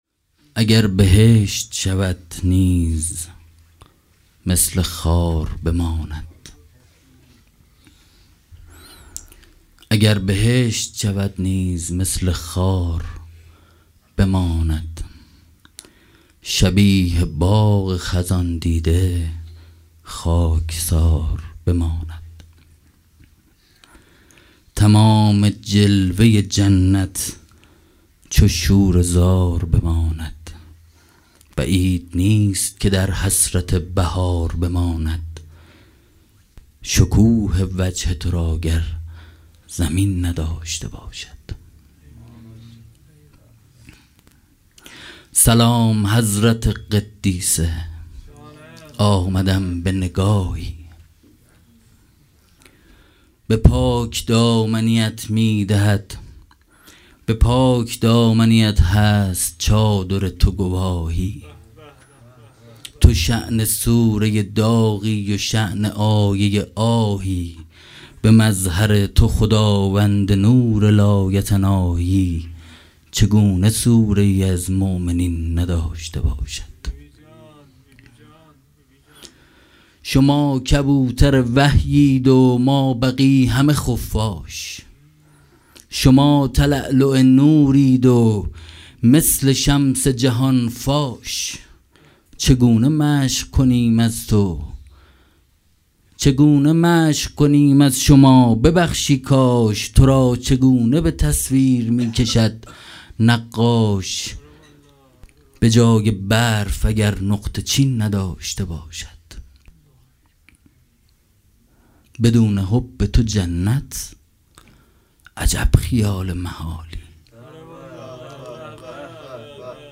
شعرخوانی
در رثای حضرت ام‌البنین سلام الله علیها در هیئت ریحانة النبی(س) منتشر شد.